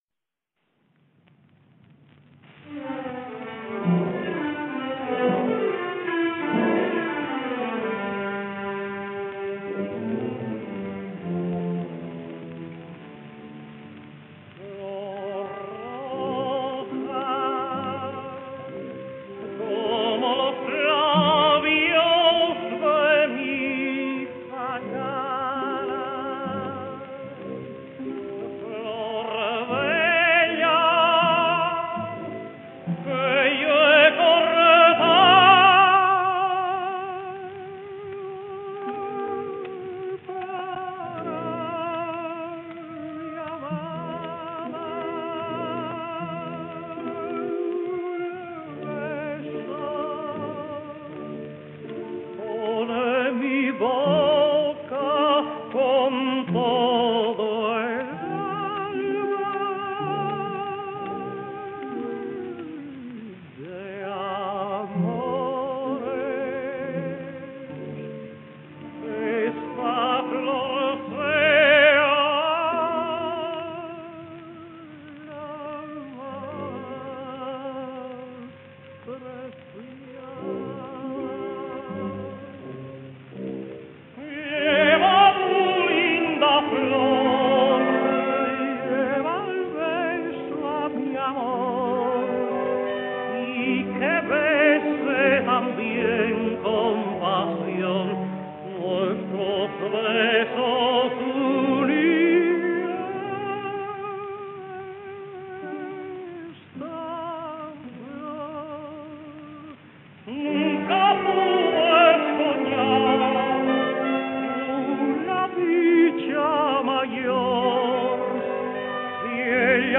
Tino Folgar sings Los gavilanes: